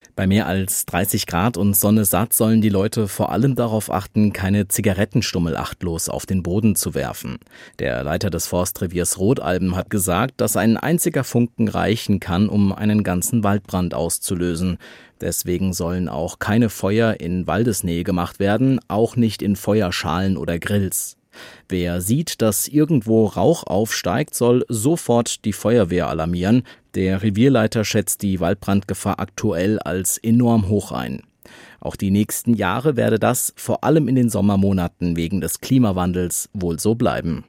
Nachrichten Waldbrandgefahr am Sonntag hoch